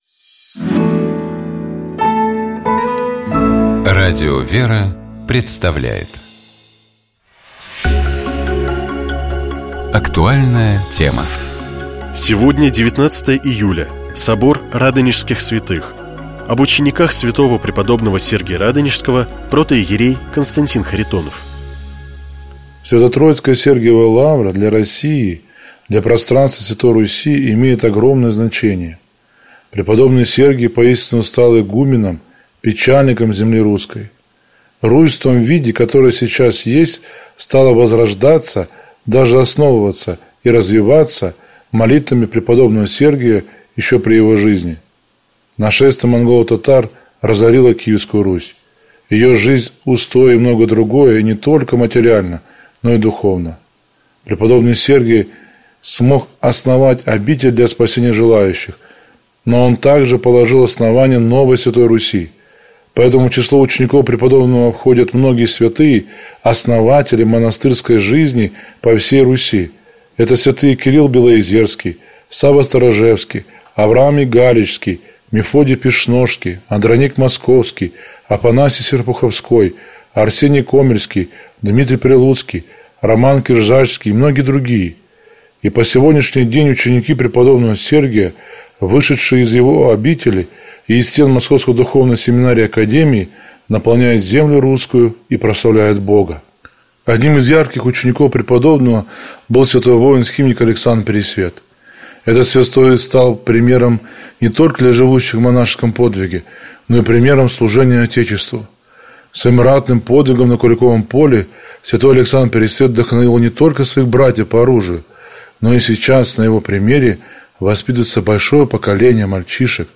Наш собеседник — историк и специалист в области христианского богословия